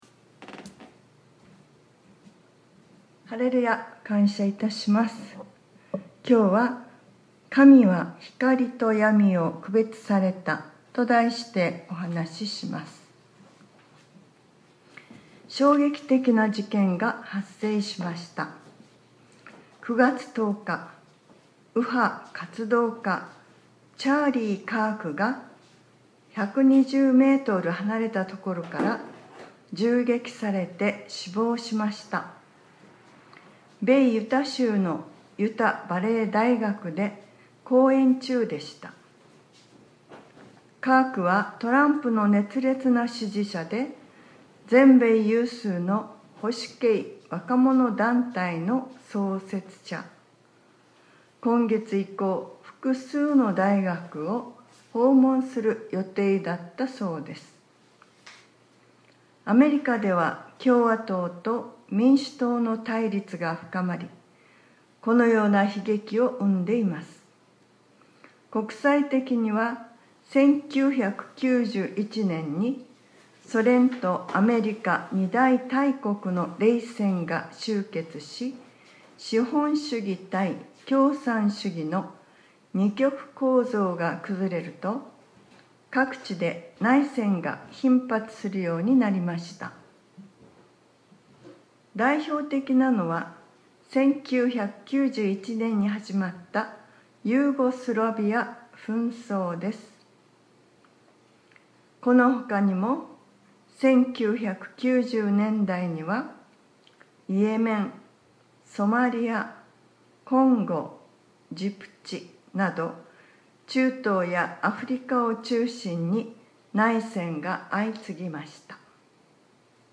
2025年09月21日（日）礼拝説教『 神は光とやみを区別された 』 | クライストチャーチ久留米教会